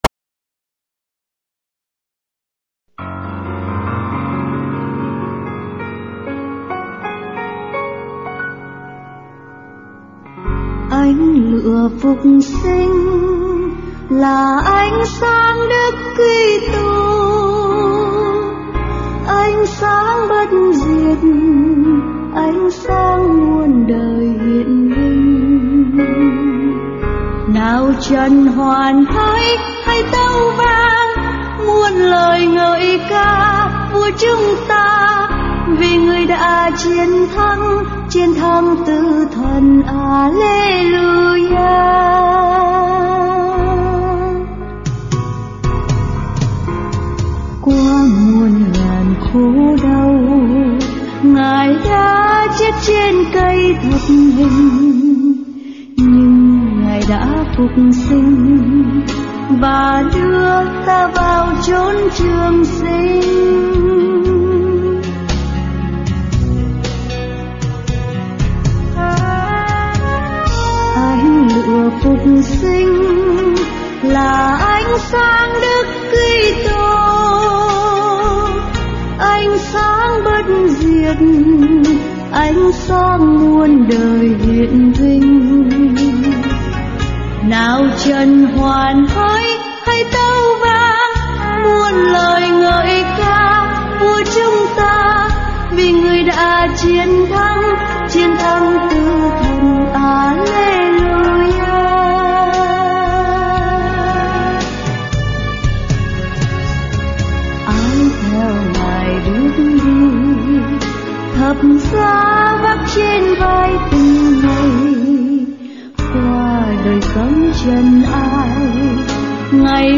Bài hát hay, ca đoàn hát cũng rất trình độ .